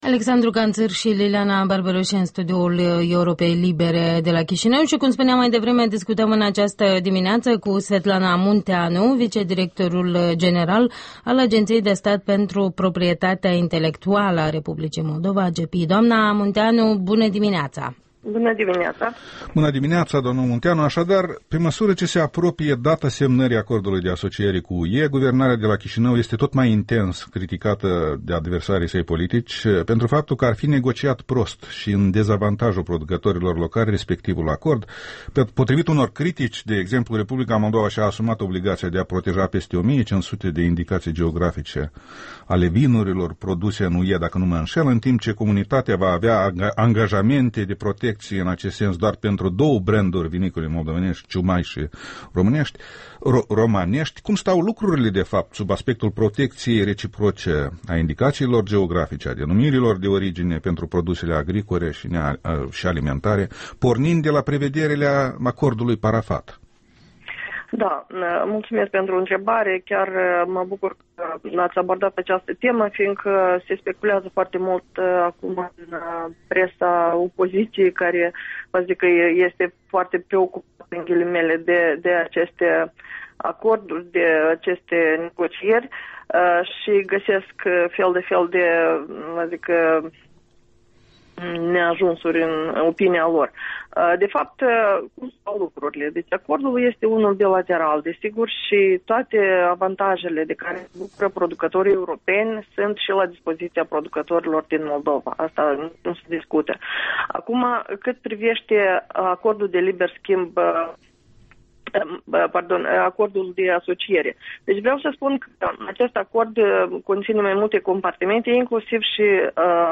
Un interviu despre denumirile de origine ale produselor moldovenești și avantajele lor în schimburile comerciale internaționale.